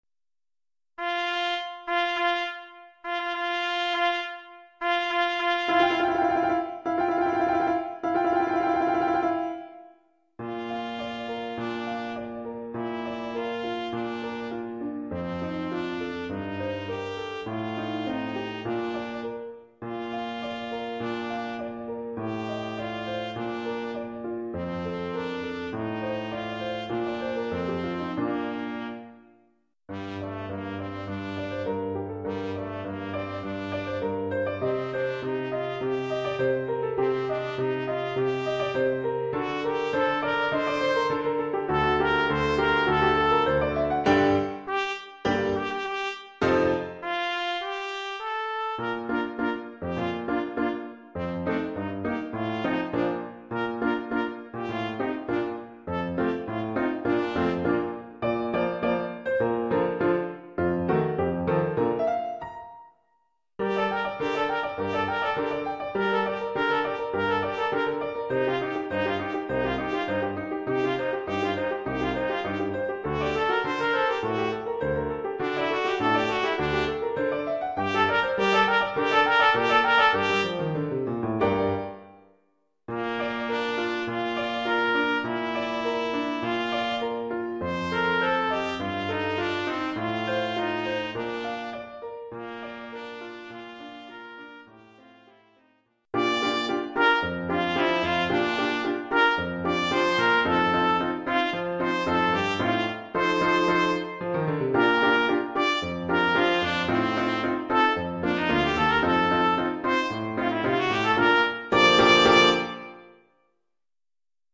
Oeuvre pour trompette ou cornet ou bugle,
avec accompagnement de piano.